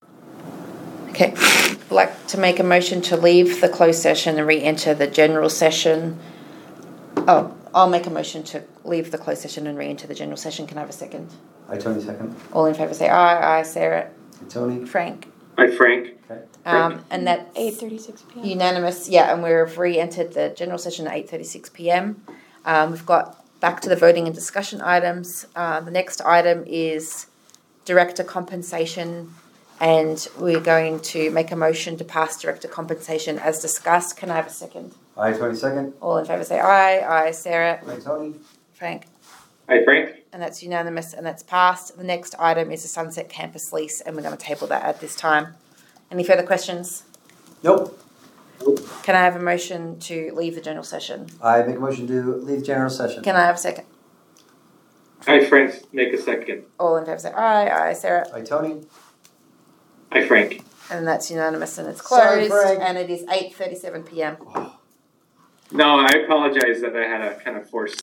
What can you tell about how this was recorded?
One or more board members may participate electronically or telephonically pursuant to UCA 52-4-207.